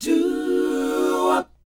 DOWOP G#4C.wav